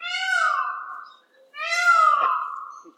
sounds_peacock_01.ogg